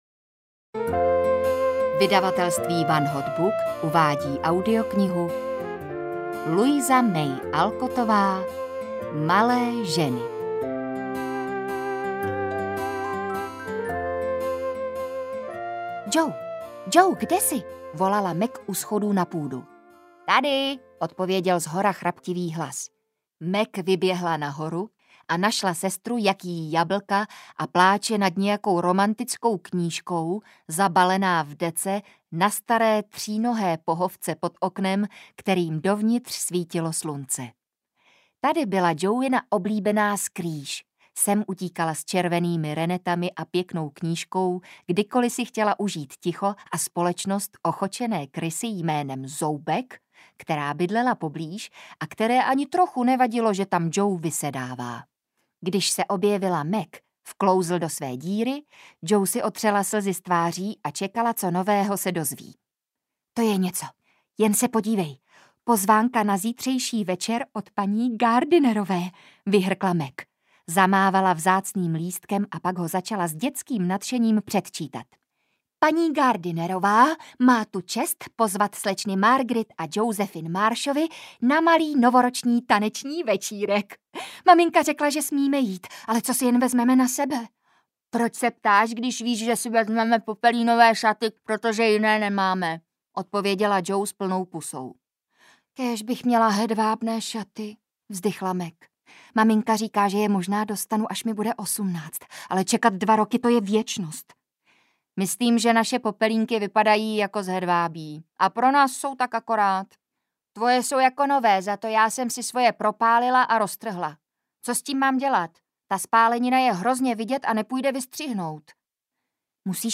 Malé ženy audiokniha
Ukázka z knihy